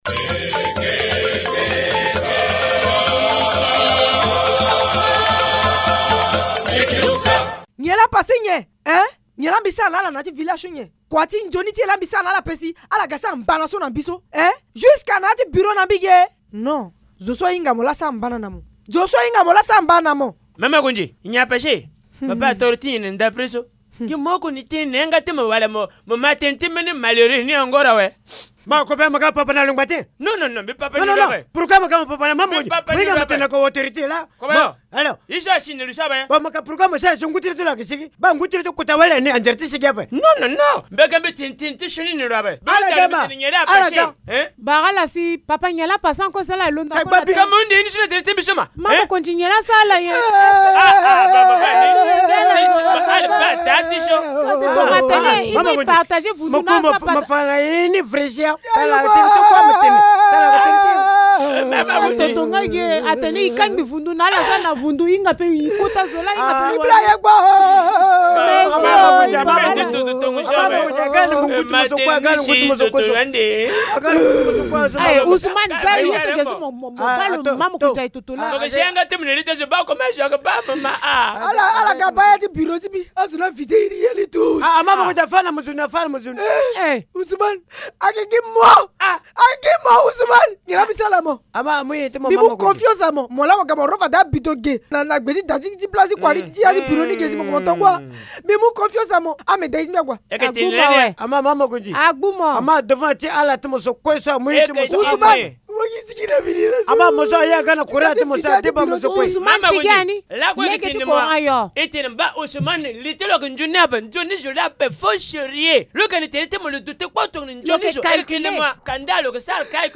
Linga théâtre : le vol ronge les services de la notabilité